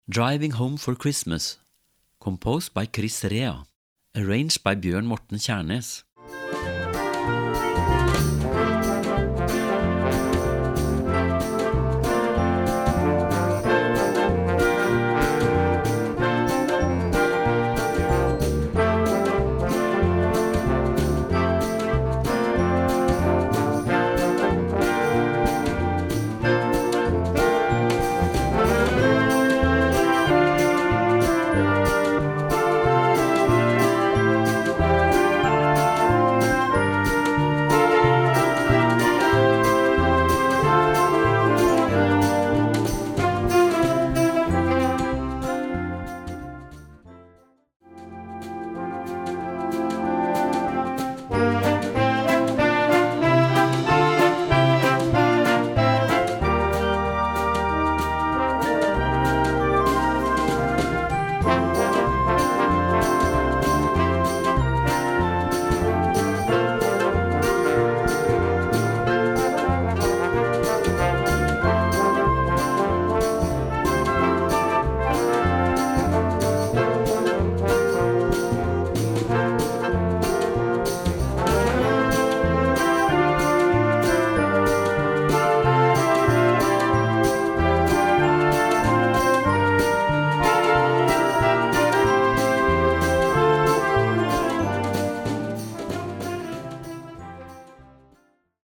Weihnachtsmusik für Jugendblasorchester
Besetzung: Blasorchester